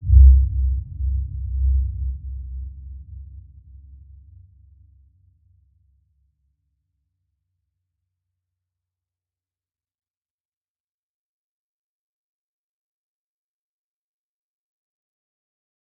Dark-Soft-Impact-C2-p.wav